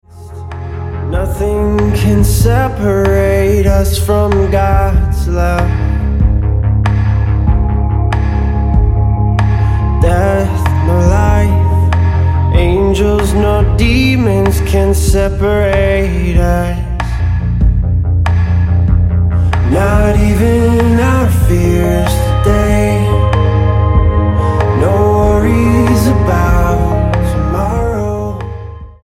Style: Ambient/Meditational